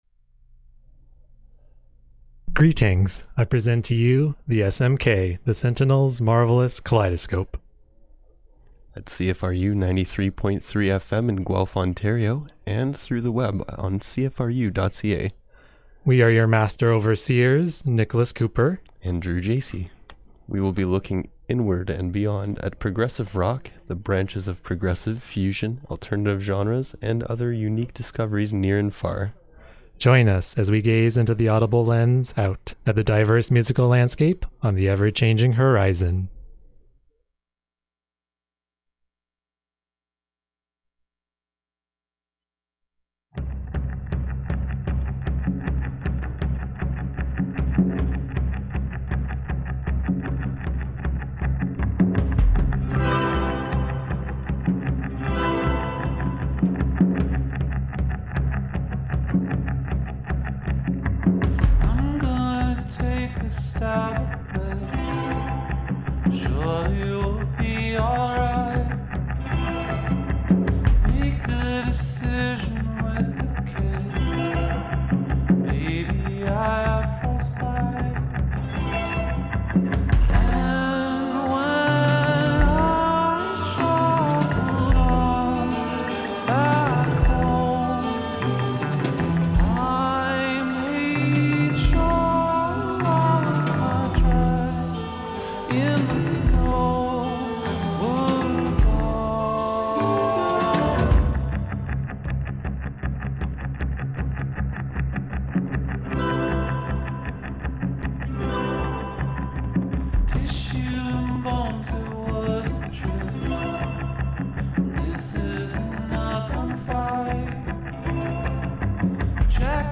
Looking inward and beyond at progressive, fusion and alternative genres, near and far...